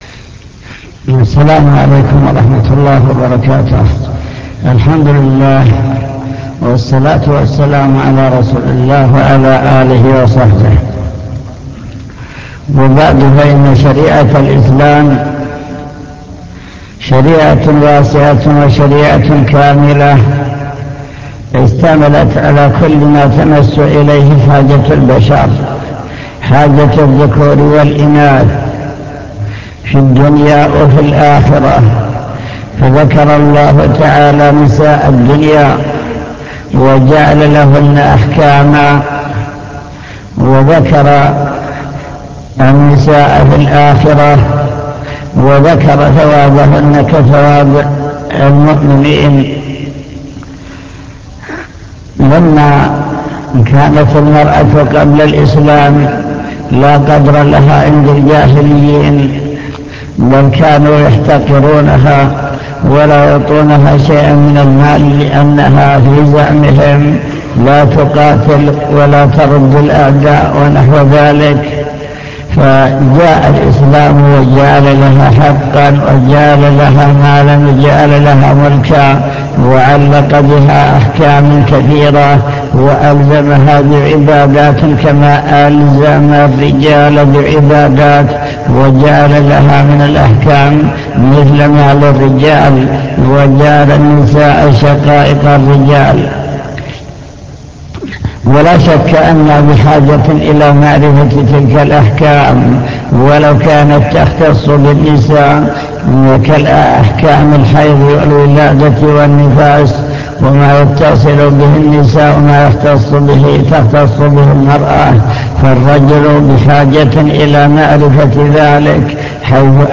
المكتبة الصوتية  تسجيلات - محاضرات ودروس  فتاوى عن المرأة